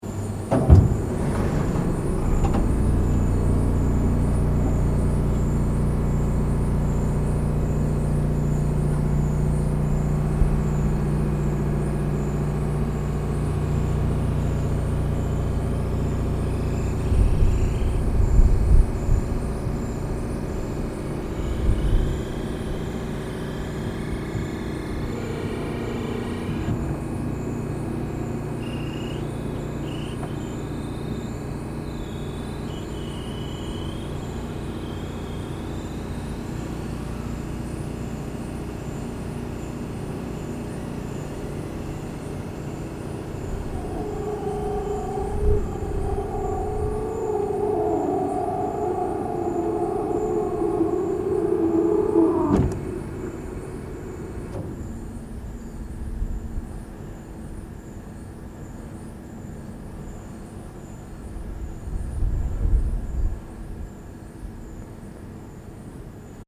Soundscape: Paranal Auxiliary Telescopes (ATs) dome opening
Like a very slow-motion Pacman pointing to the sky, the Auxiliary Telescopes (ATs) opens its round enclosure after a day test previous to a night of observations. Although the opening sound of this dome is quite discreet, this audio also captures sounds of the AT´s electronic cabinets, liquid cooling system and air-conditioning units.
Soundscape Mono (mp3)
ss-paranal-at-dome-opening_mono.mp3